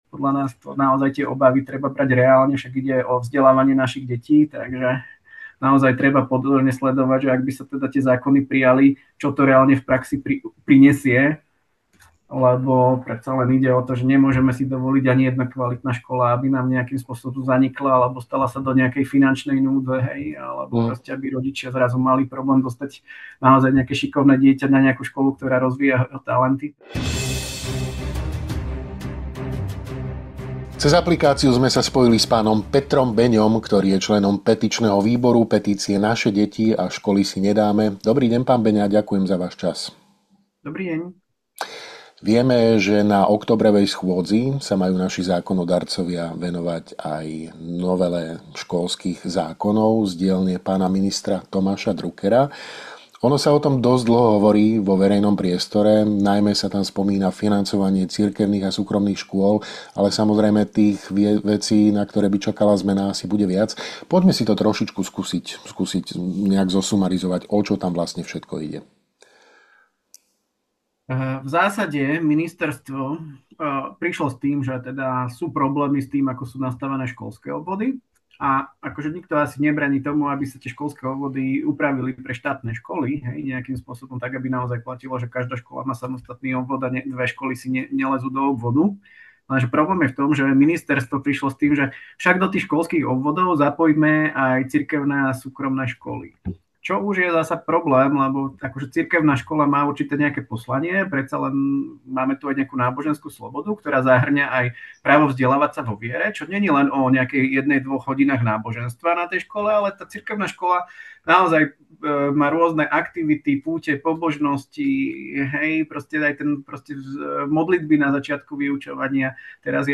S predstaviteľom petičného výboru sme sa rozprávali o tom, čo im na návrhu novely najviac prekáža a aké problémy by nás po jej prijatí v aktuálnej podobe čakali.